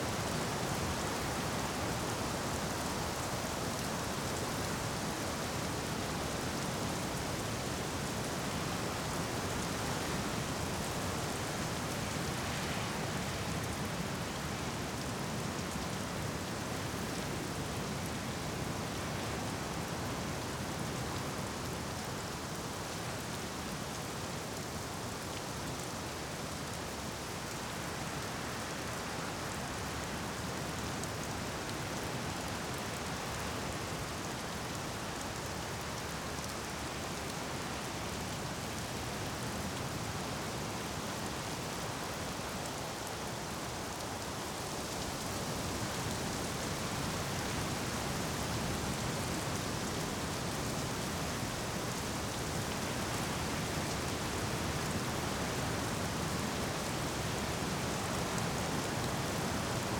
Sea Rain.wav